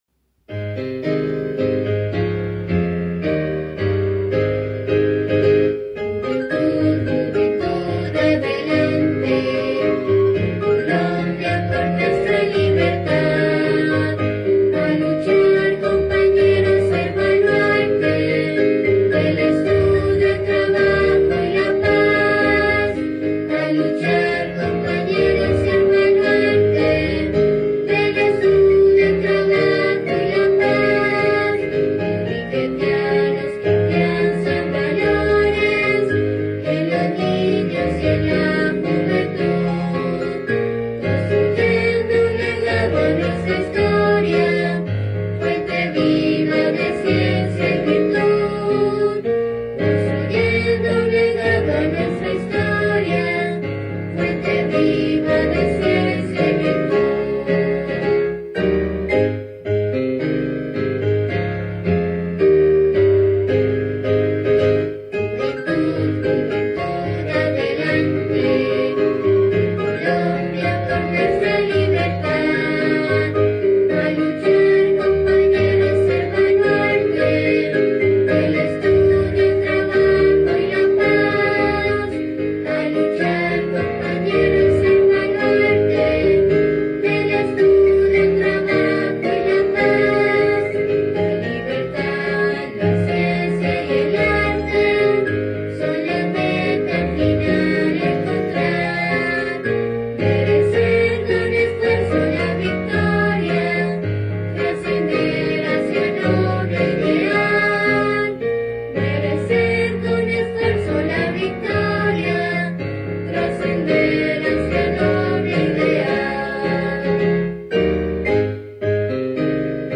HIMNO